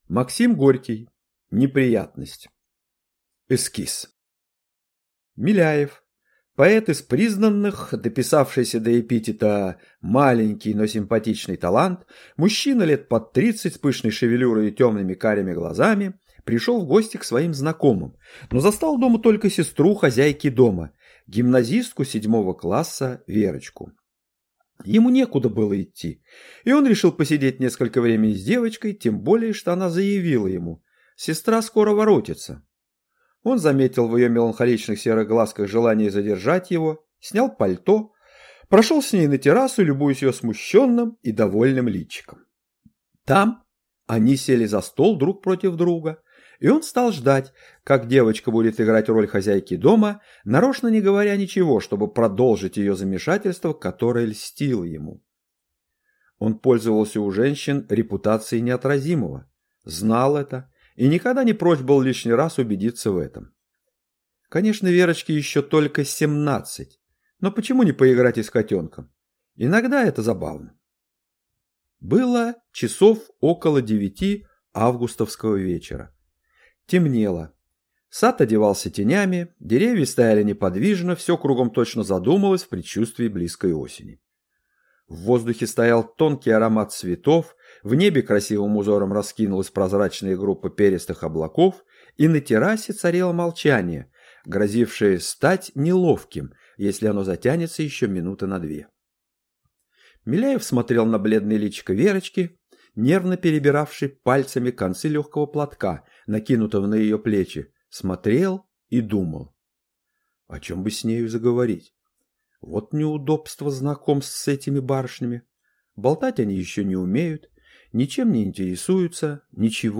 Аудиокнига Неприятность | Библиотека аудиокниг